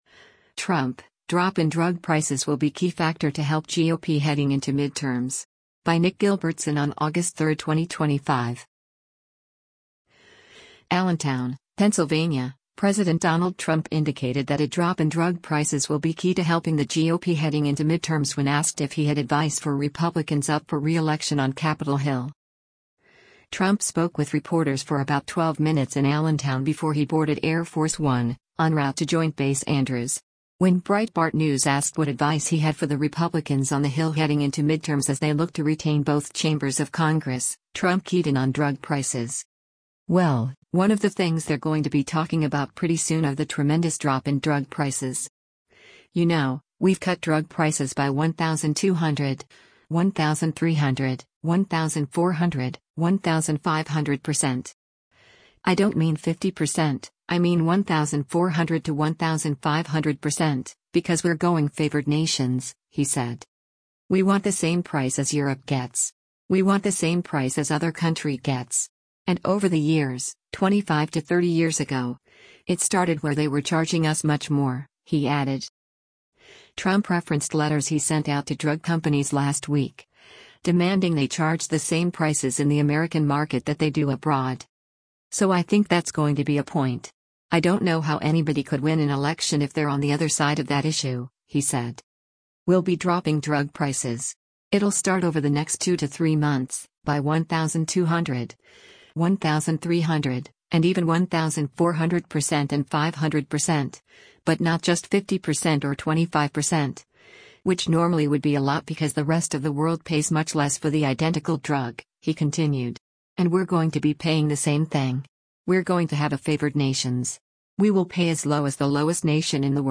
ALLENTOWN, PENNSYLVANIA - AUGUST 03: U.S. President Donald Trump speaks to reporters near
Trump spoke with reporters for about 12 minutes in Allentown before he boarded Air Force One, en route to Joint Base Andrews.